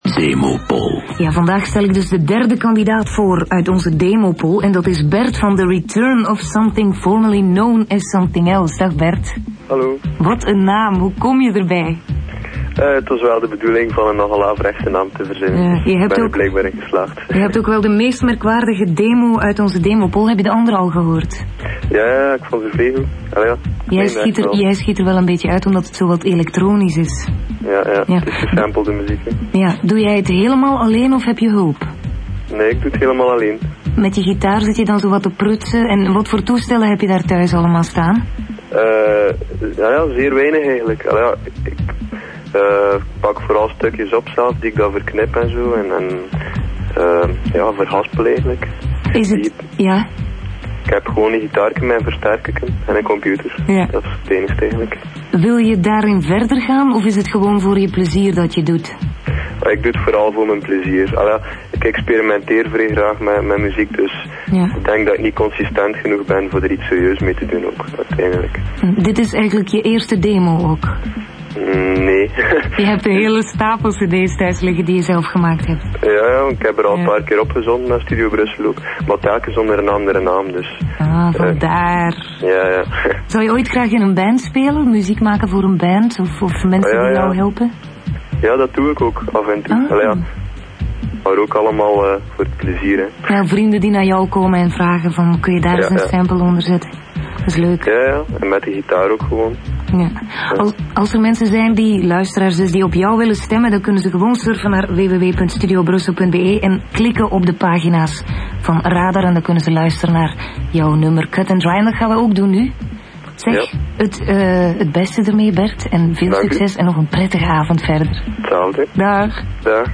Fun fact: this album was one of the contenders of ‘Demopoll’ (in radioshow ‘Radar’), where demos were played on Belgian radio station Studio Brussel, somewhere in 2000, then presented by Roos Van Acker. This even included a small interview-by-telephone live on radio.  The best part: the project name ‘The Return Of Something Formerly Known As Something Else’ got fully (and flawlessly!) announced on national radio 🙂 And then the track ‘Cut-And-Dry’ was meant to be played, but the track ‘Titan Wise’ got played instead (which is a much weirder track).